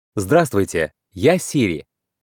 Звуки MacBook Pro и iMac скачать mp3 - Zvukitop
9. Здравствуйте, я Сири (мужской голос)